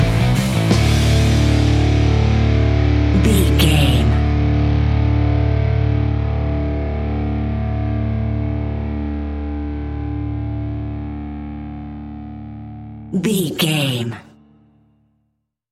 Epic / Action
Aeolian/Minor
Slow
hard rock
heavy metal
horror rock
Heavy Metal Guitars
Metal Drums
Heavy Bass Guitars